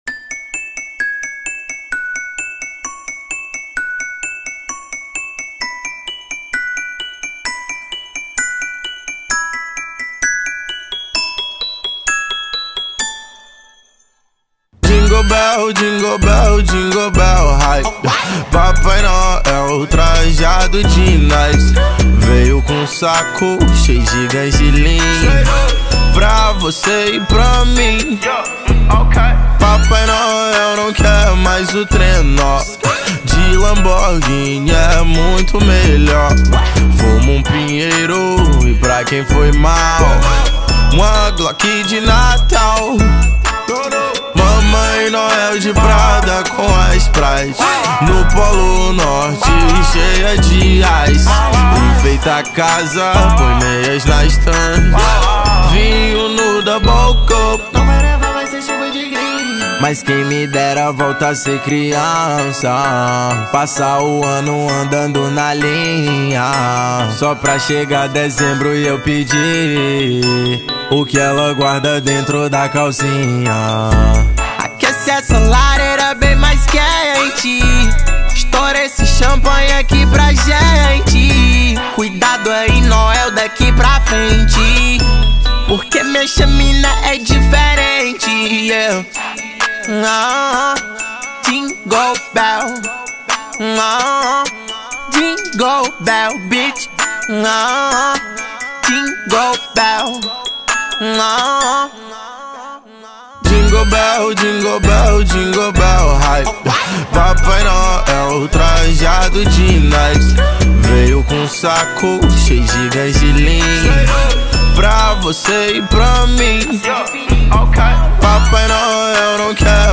2024-11-06 09:10:46 Gênero: Trap Views